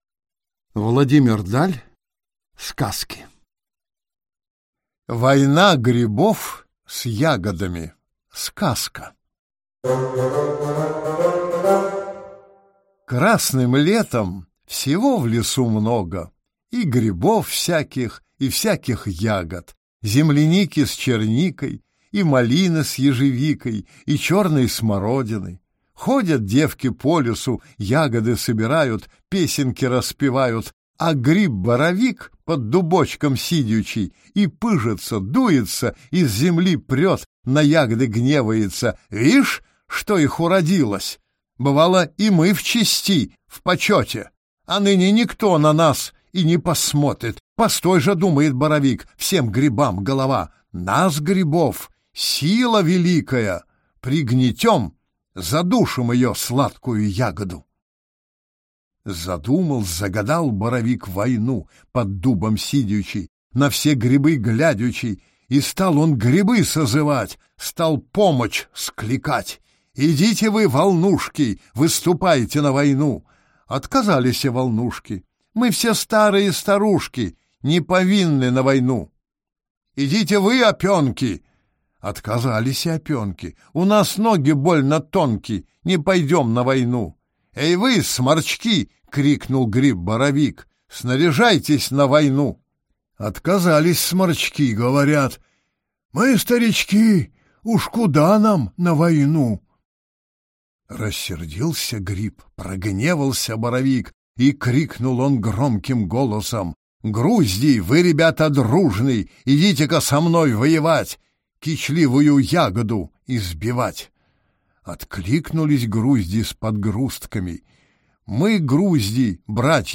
Аудиокнига Сказки | Библиотека аудиокниг